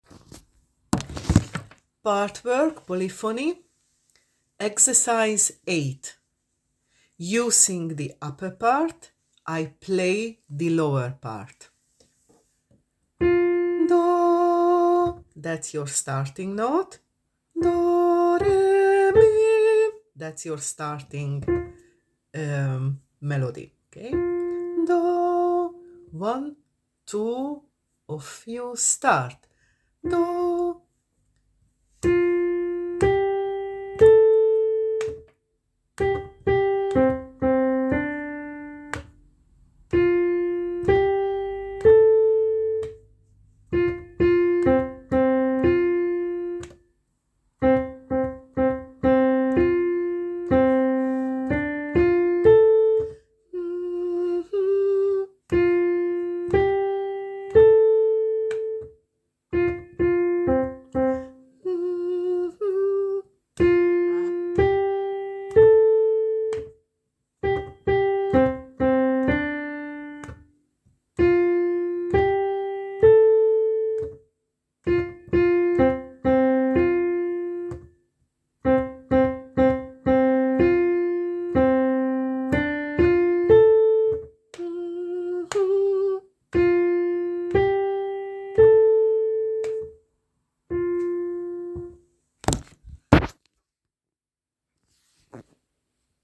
Sing it a few times, then sing it with the recording of the lower part: